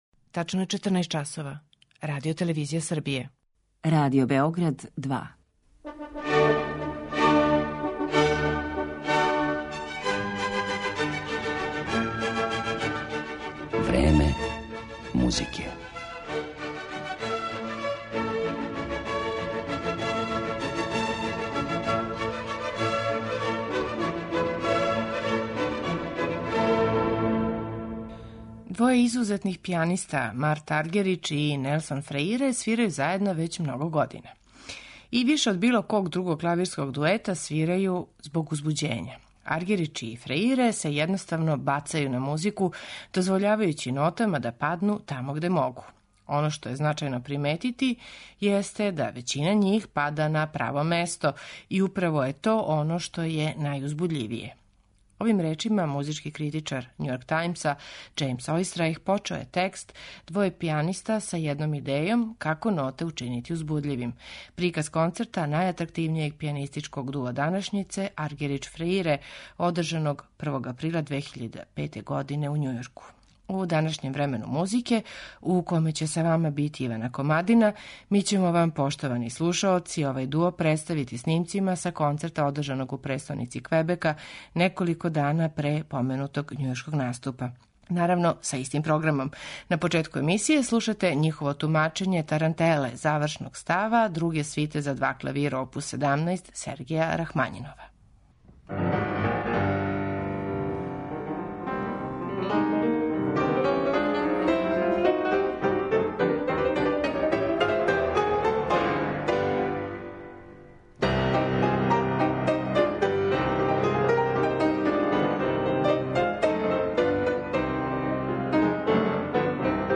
Клавирски дуo